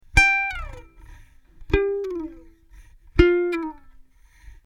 A slide-out is a falling kind of effect that rushes by a whole lot of notes fast.
slide-out.mp3